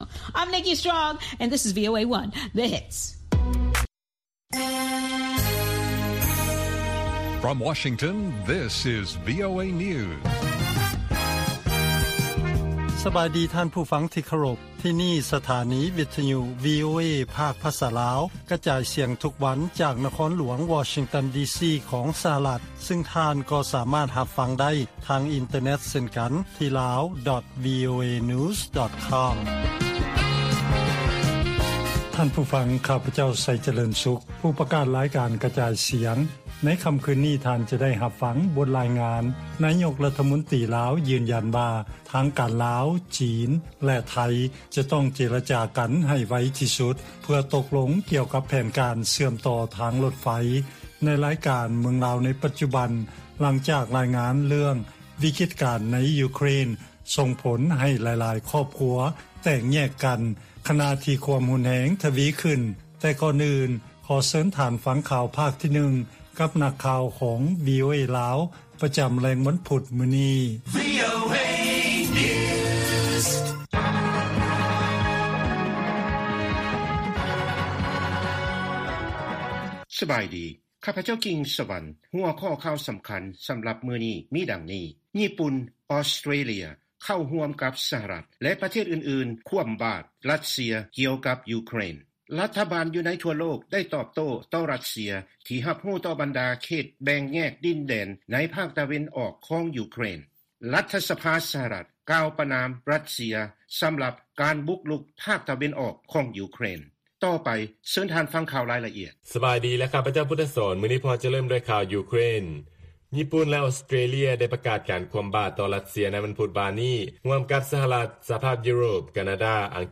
ລາຍການກະຈາຍສຽງຂອງວີໂອເອ ລາວ: ຍີ່ປຸ່ນ, ອອສເຕຣເລຍ ເຂົ້າຮ່ວມກັບ ສະຫະລັດ ແລະ ປະເທດອື່ນໆ ຂວໍ້າບາດ ຣັດເຊຍ ກ່ຽວກັບ ຢູເຄຣນ.